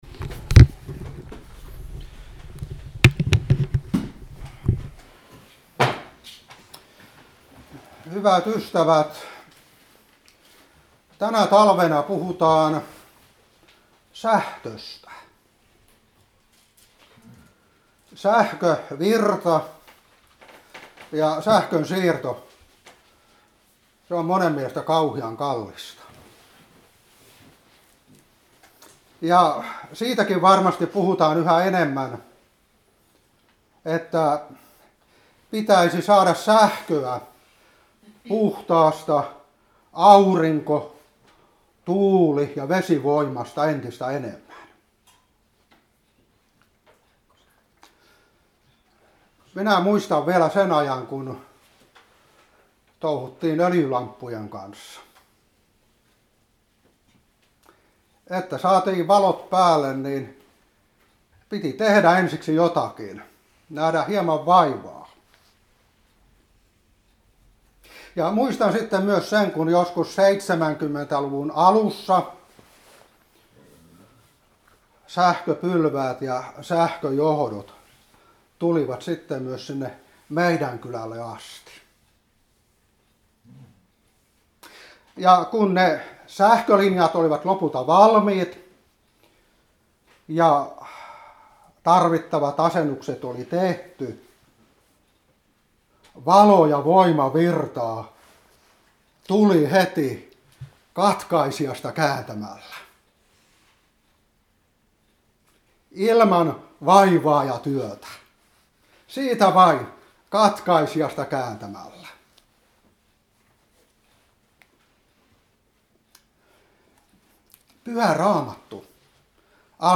Seurapuhe 2023-1. 1.Moos.1:2-3. Joh.3:5,8. 1.Joh.1:5-7.